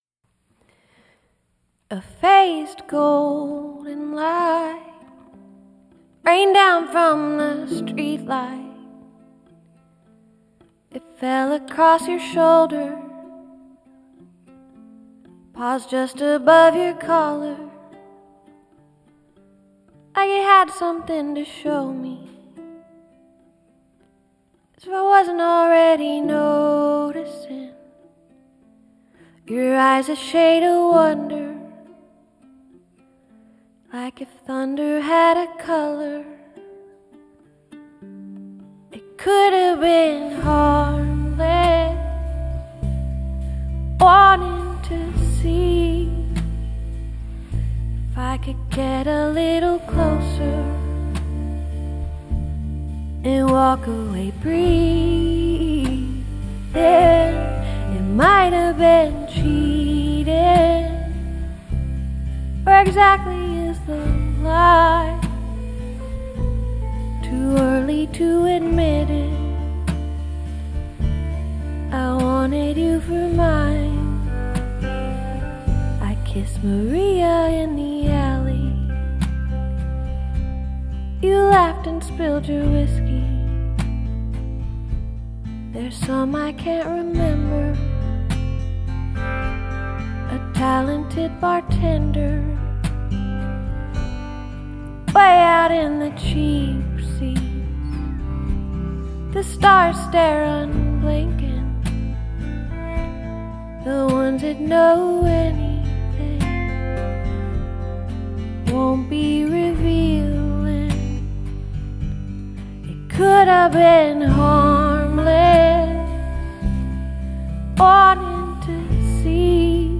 Coldly confident songwriting drives these country tunes